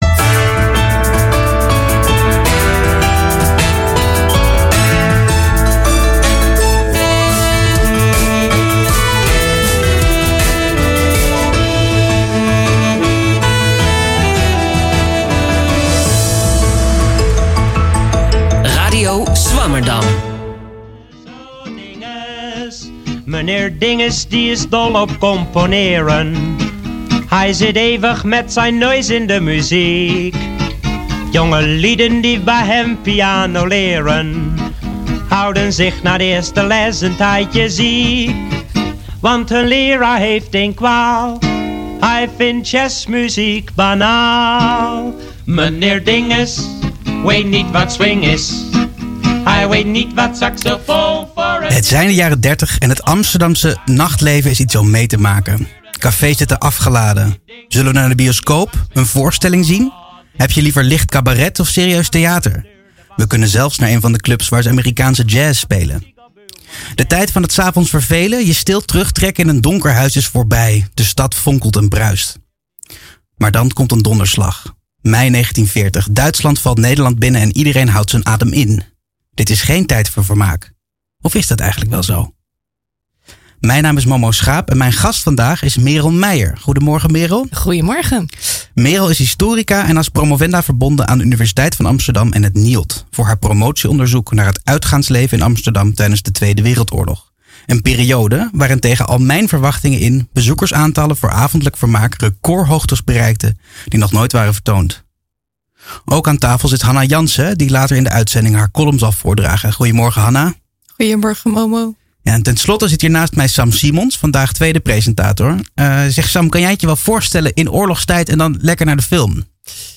Radio Swammerdam is hét wekelijkse radioprogramma over Amsterdamse wetenschap sinds 2 mei 2010.
In onze knusse studio in Pakhuis de Zwijger schuiven wetenschappers aan om hun onderzoek uitgebreid en toegankelijk toe te lichten.